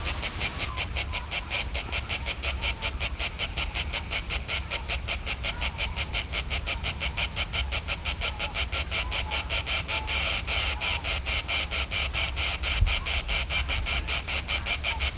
Non-specimen recording: Soundscape Recording Location: Europe: Greece: Silver Island
Recorder: iPhone 6